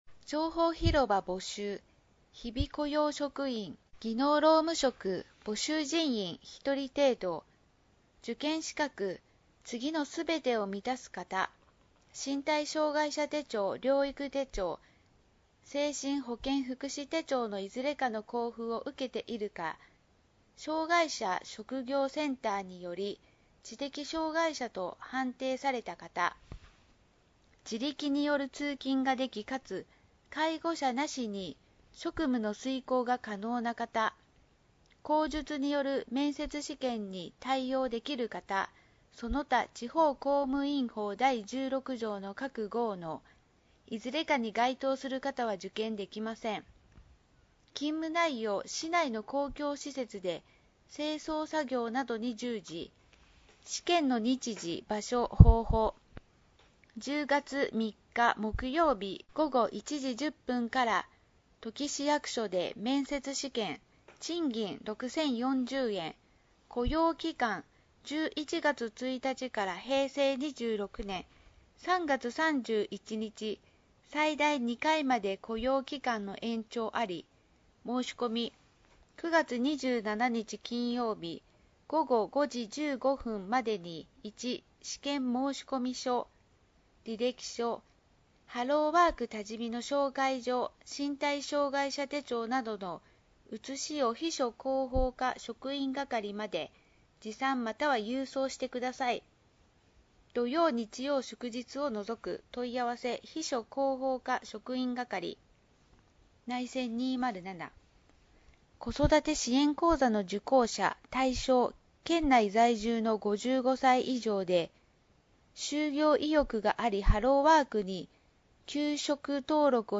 音声欄に表示があるものは、「声の広報」として、音声にてお聴きになれます。